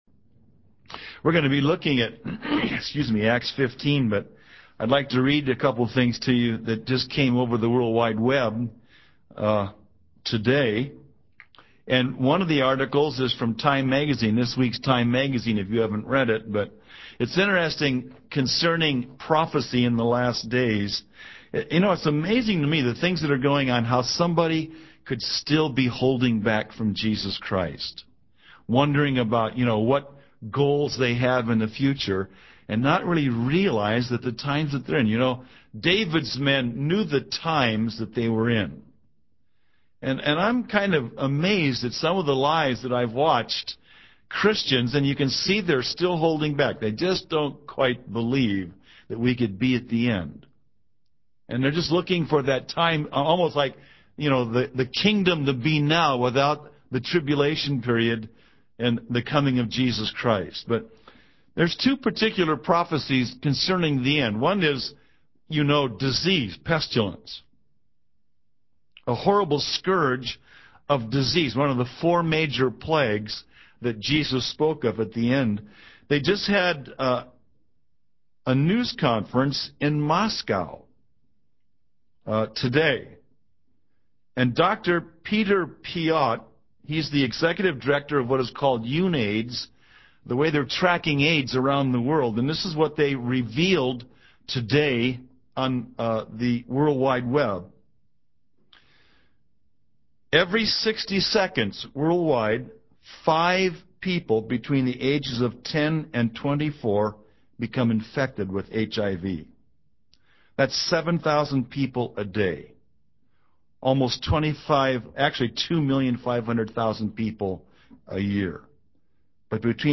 In this sermon, the preacher emphasizes the importance of apprehending grace in the life of a believer. He highlights that grace is more difficult to understand and embrace than getting rid of sin. The preacher encourages the audience to labor to enter into rest, referring to the perfect Sabbath rest found in Jesus Christ.